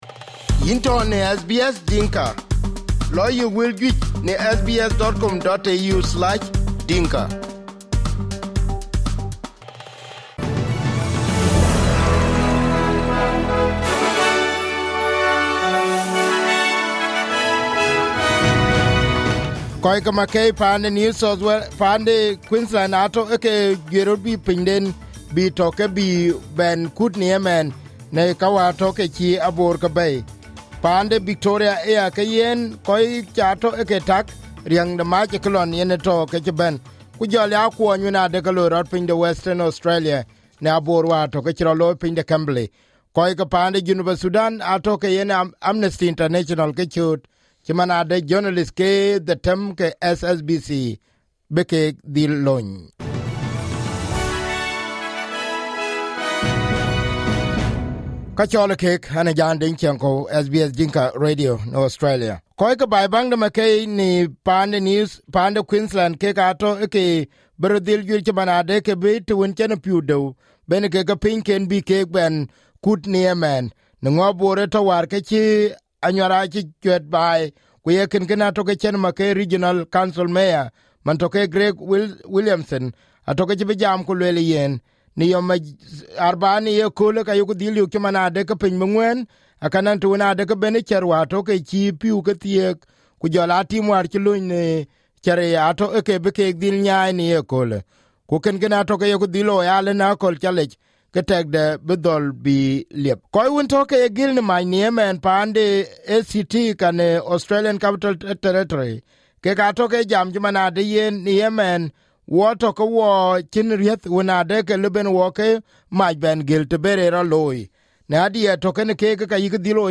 SBS Dinka News 18/01/2023